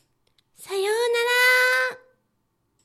ダウンロード 中性_「さようなら(笑)」
リアクション中音挨拶